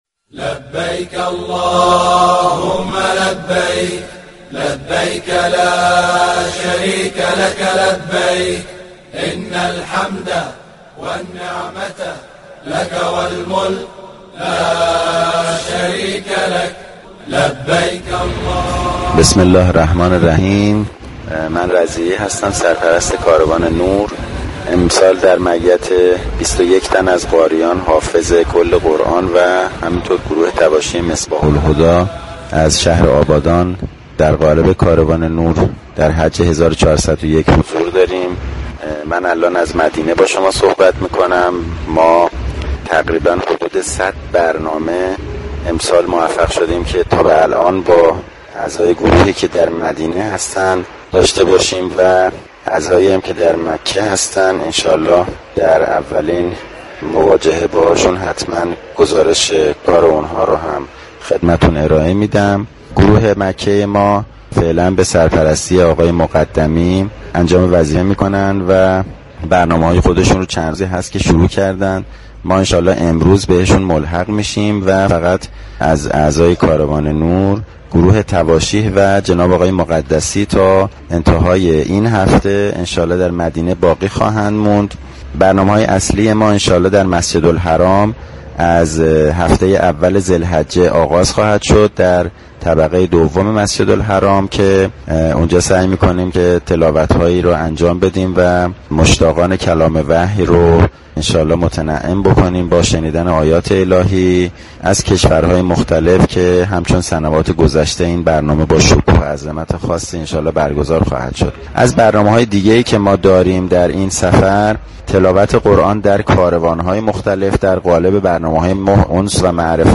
ارائه گزارشی از برنامه های اجرایی كاروان قرآنی حج تمتع 1401 كشور